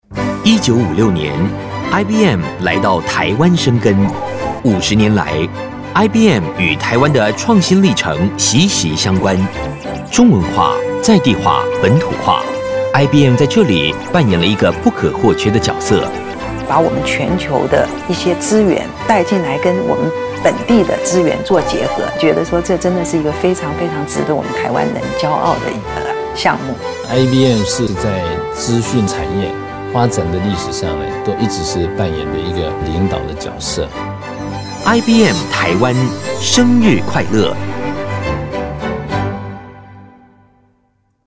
台語配音 國語配音 男性配音員
他擁有多變且廣闊的音域，從青春洋溢的少年代角色到沉穩成熟的中年人物皆能詮釋自如。